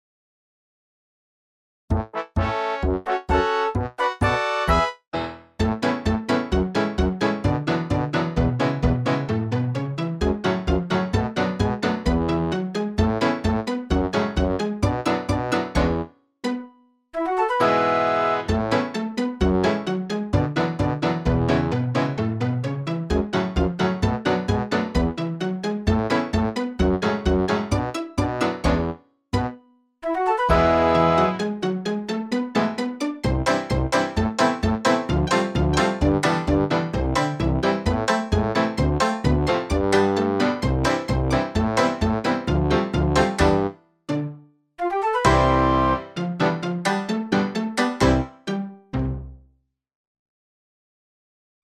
The demo audio 7.mid encloses a combination of instruments.